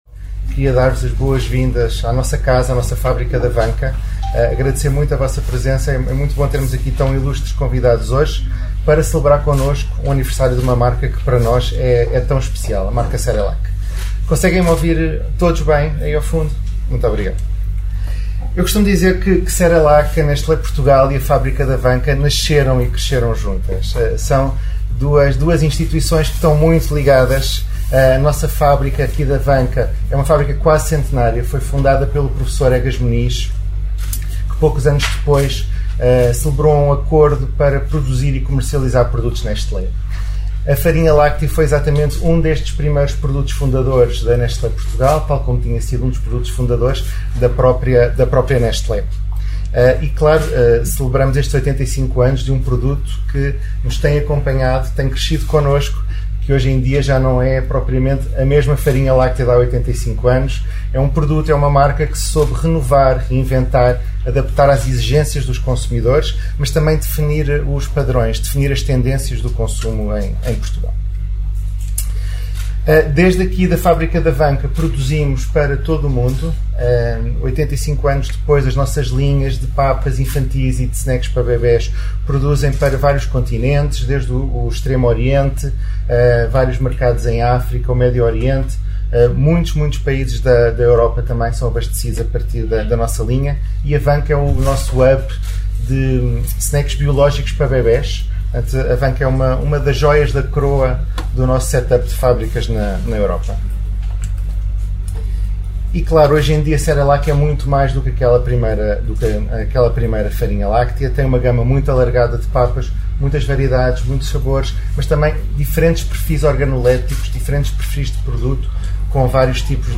Após estas introduções, que pode ouvir na integra clicando nos players respetivos, a organização das celebrações levou os convidados a visitar parte das instalações fabris, num esforço de bem receber revestido de uma logística complexa, no intuito de garantir a segurança máxima, apanágio de toda a organização.